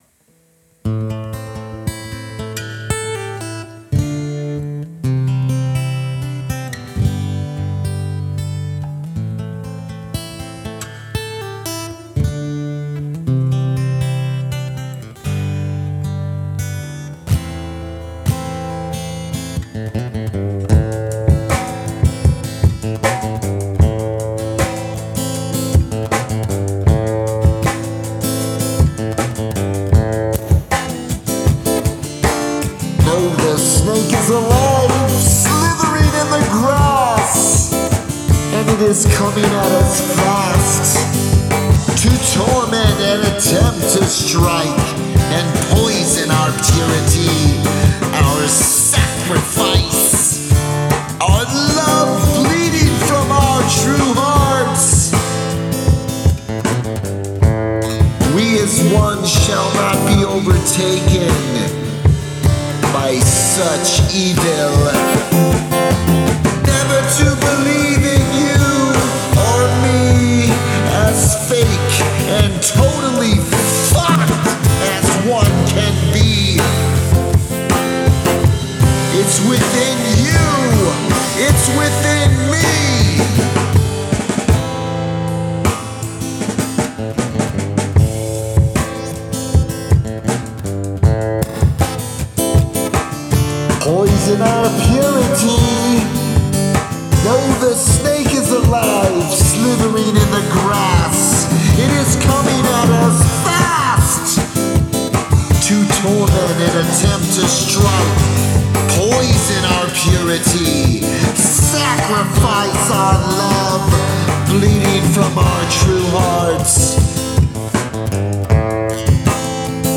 Percussion
Acoustic Guitars
Bass Guitar
Predemo